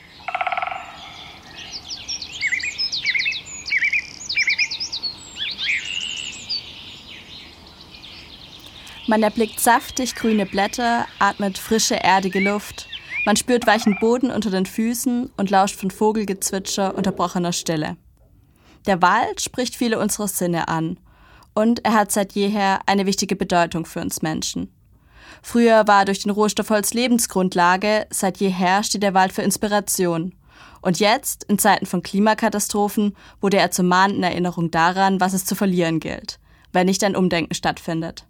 Radio Micro-Europa: Sendung 380 „Wald als Bildungsort“ Sonntag, den 22. August 2021 von 12 bis 13 Uhr im Freien Radio Wüste Welle 96,6 – Kabel: 97,45 Mhz, auch als Live-Stream im Internet.
Musik: